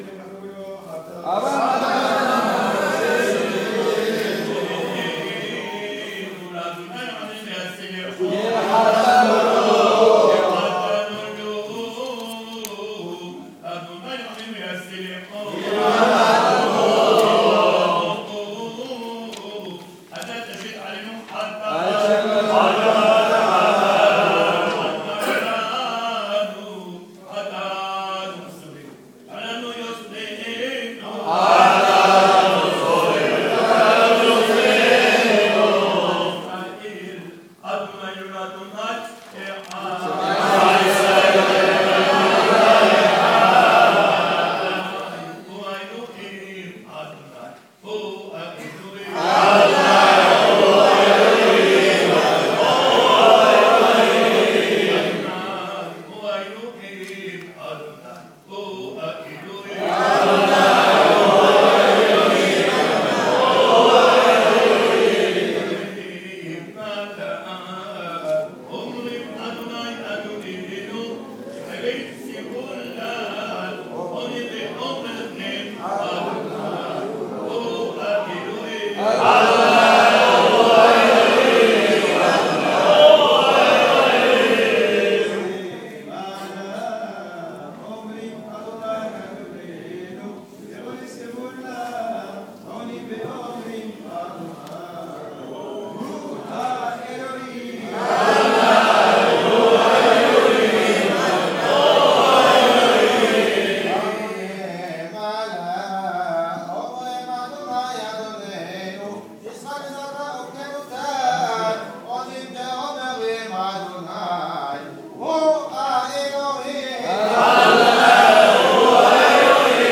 LECTURES DES SELIHOTES EN DIRECT .....
selihot_dimanche_matin_jeune2.mp3